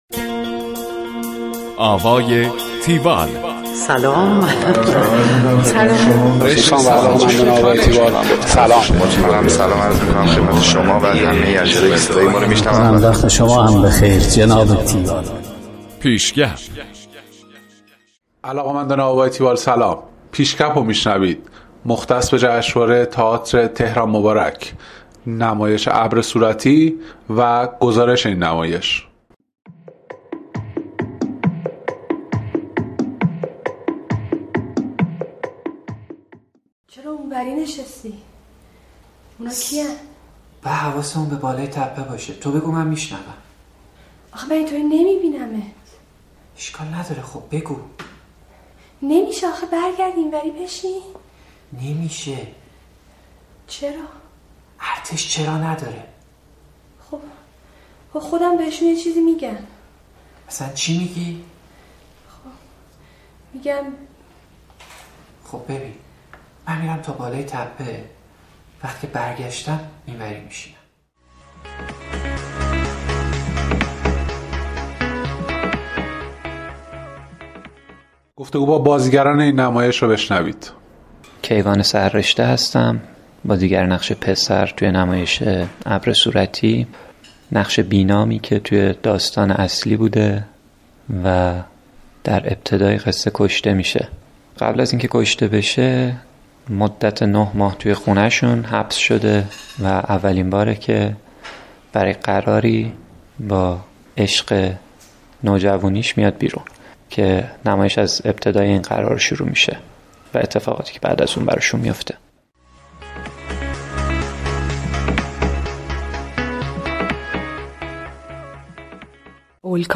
گزارش آوای تیوال از نمایش ابر صورتی
گفتگو با
حسن معجونی / کارگردان